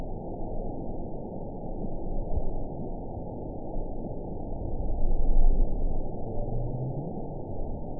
event 917881 date 04/19/23 time 15:50:41 GMT (2 years ago) score 8.38 location TSS-AB05 detected by nrw target species NRW annotations +NRW Spectrogram: Frequency (kHz) vs. Time (s) audio not available .wav